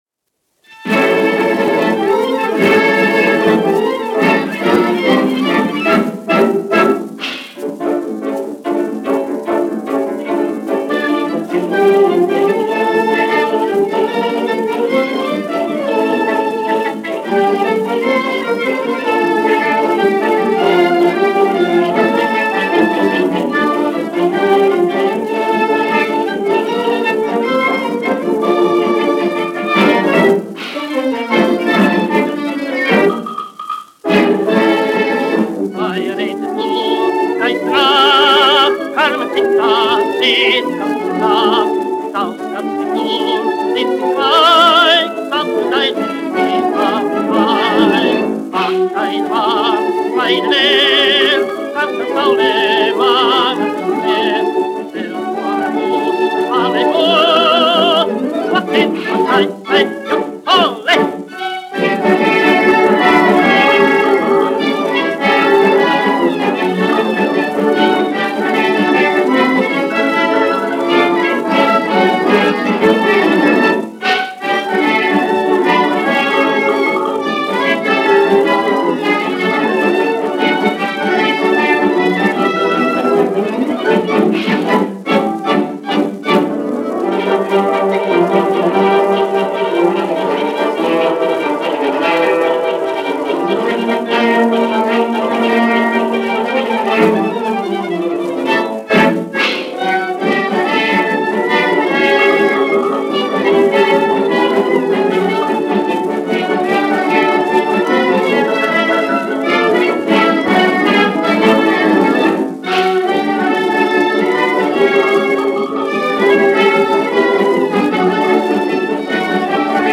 dziedātājs
1 skpl. : analogs, 78 apgr/min, mono ; 25 cm
Sarīkojumu dejas
Populārā mūzika
Latvijas vēsturiskie šellaka skaņuplašu ieraksti (Kolekcija)